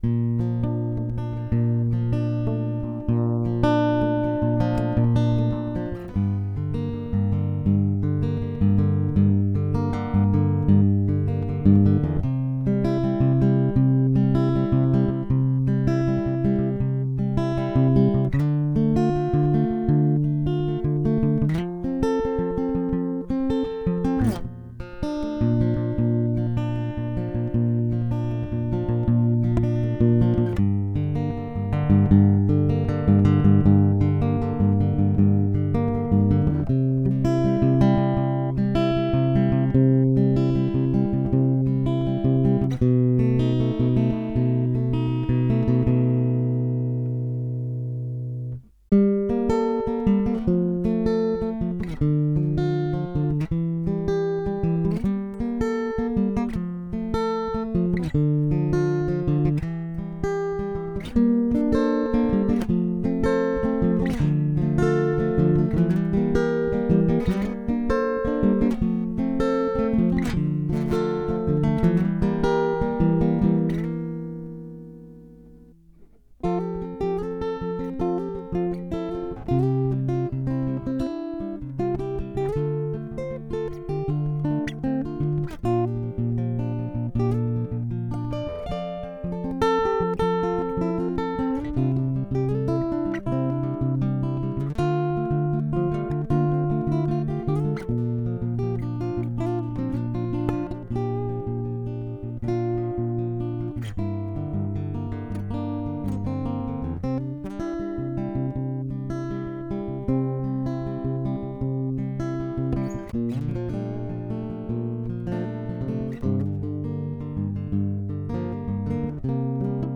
Jeden Tag im Oktober ein Musikstück für die richtige Halloween-Stimmung.